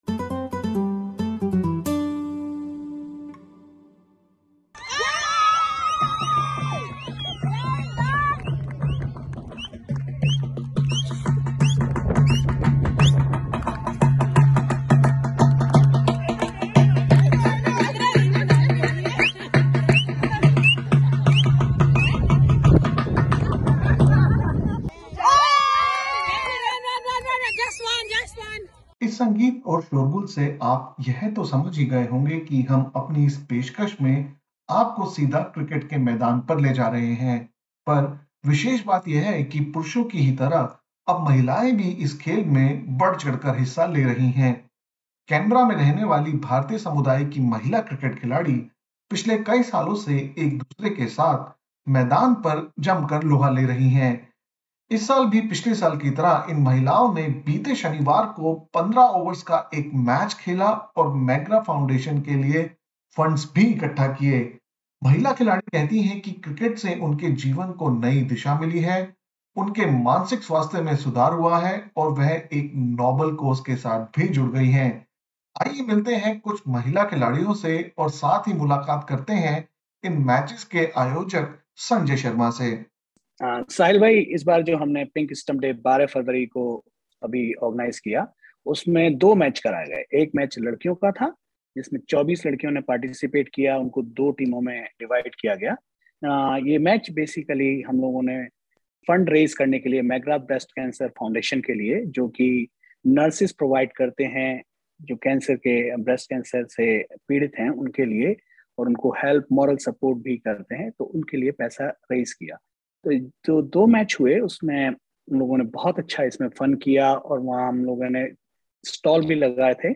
Men and women teams from the Indian community and an Indian High Commission team participated in Pink Stumps Day last week in Canberra. They raised about $1100 for McGrath Foundation, which provides nurses for breast cancer patients. Some women cricket players, who participated for the first time, told SBS Hindi that this cause has helped them break mental barriers and brought the community closer.